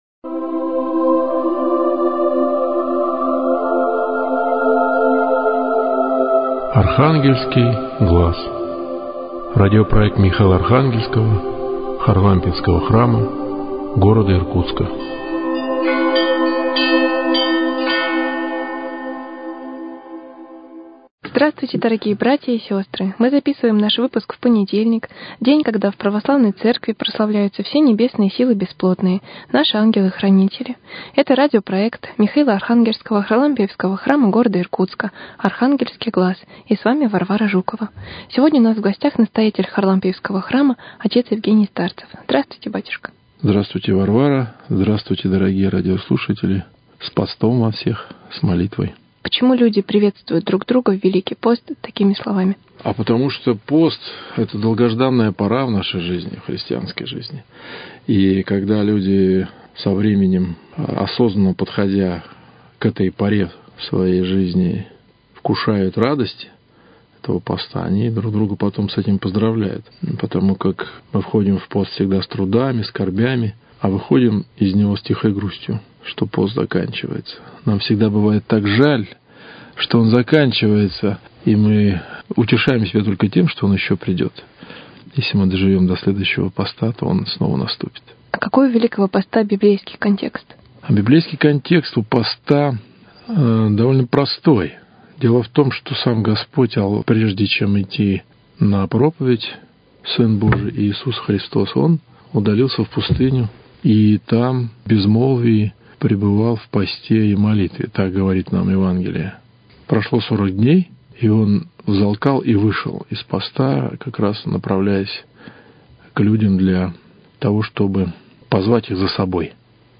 Прошла первая неделя Великого поста и мы подводим промежуточные итоги. Беседа